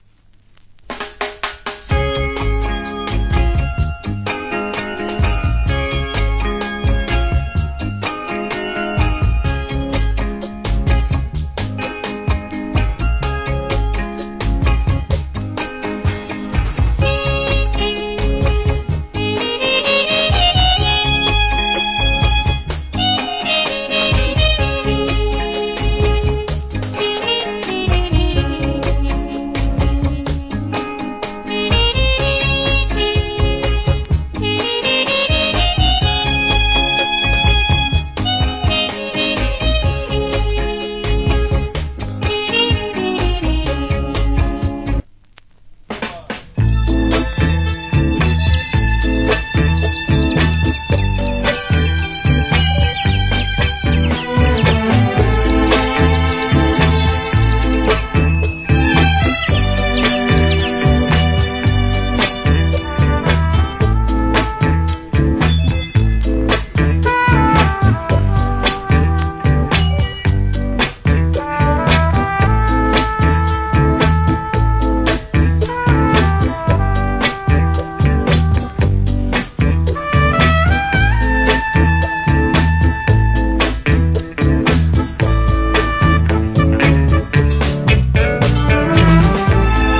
Nice Inst.